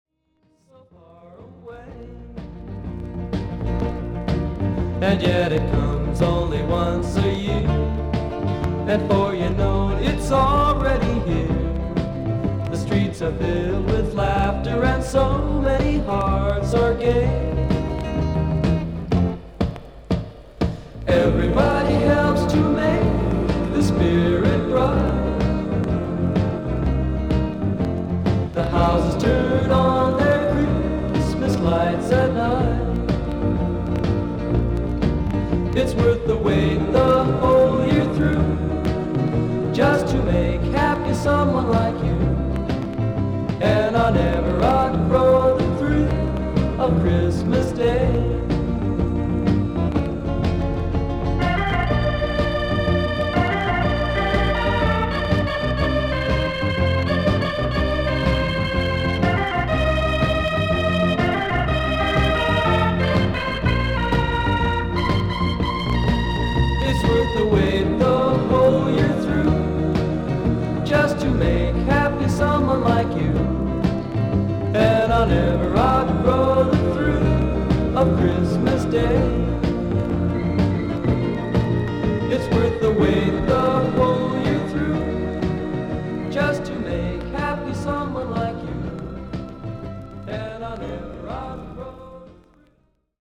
少々周回ノイズあり。
少々サーフィス・ノイズあり。クリアな音です。